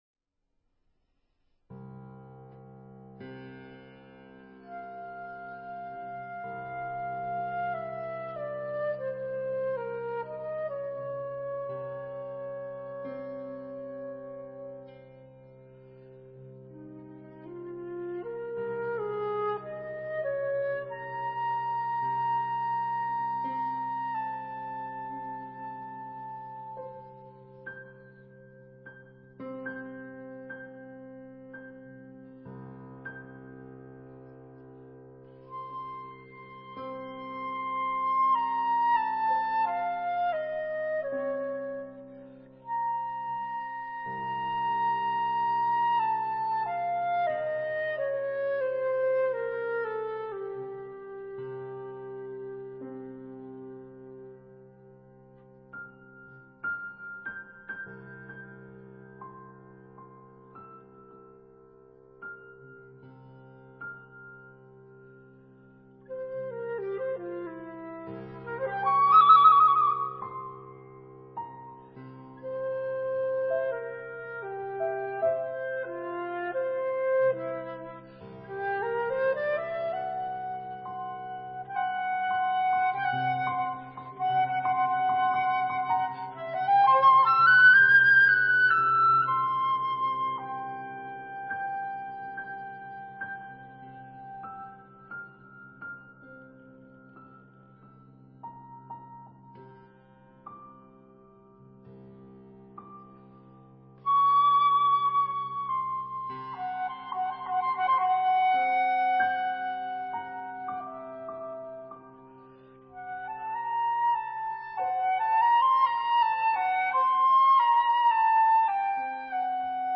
flûte
piano